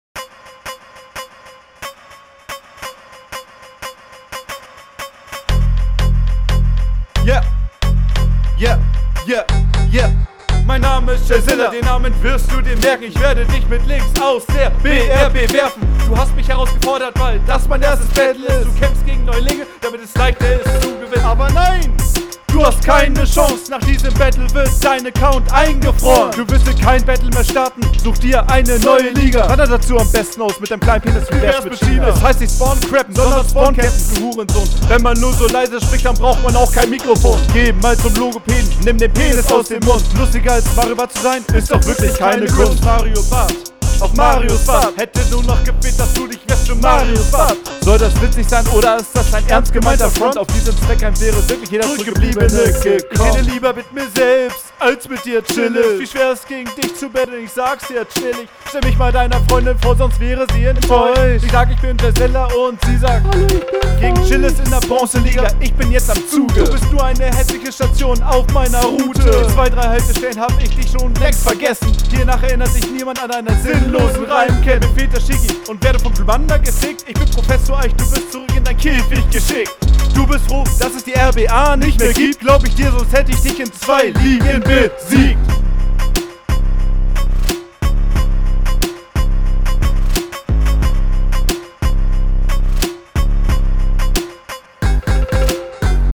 hast du den beat ganz komisch gemischt oder hast du kicks oder was auch immer …